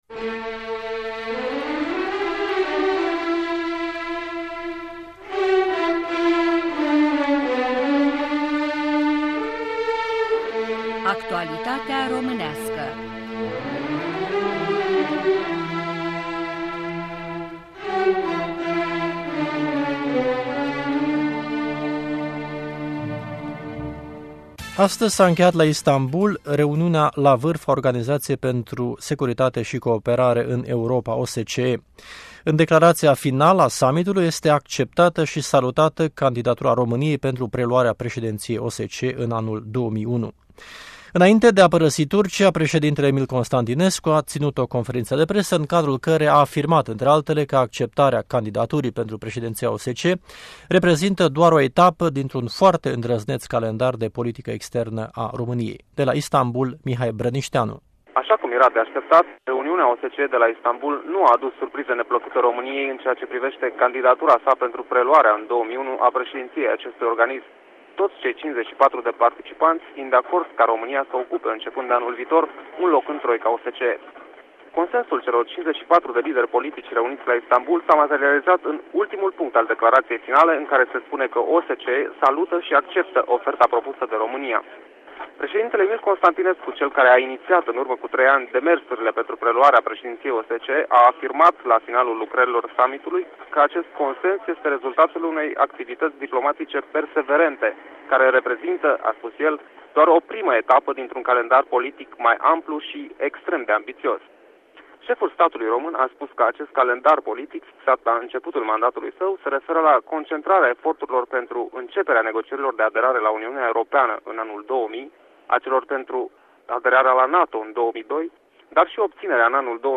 Conferința de presă a președintelui Constantinescu la încheierea summitului OSCE la Istanbul